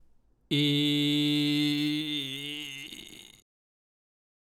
次に、G(い)+仮声帯(普通からじりじり入れる)